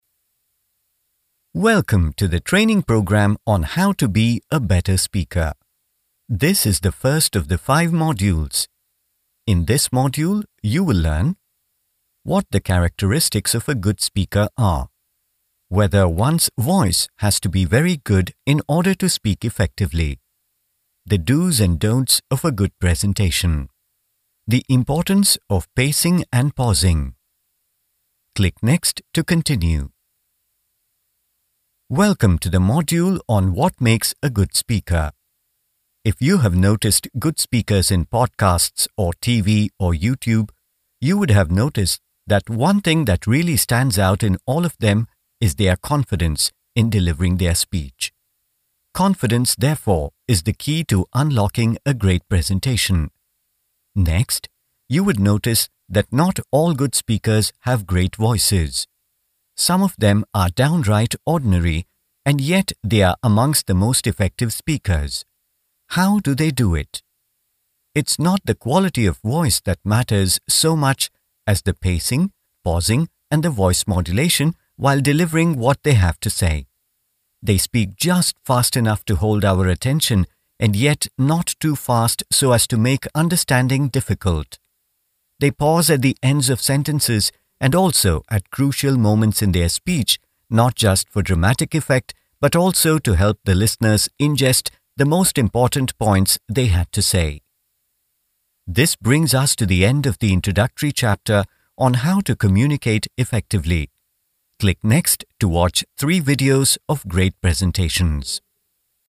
Male
Warm, friendly, soothing voice with a good balance of bass and clear high end.
Middle-aged Neutral English accent tilted towards British/Indian with clear enunciation, and judicious pace.
E-Learning
A Typical E-Learning Read
1202e_learning.mp3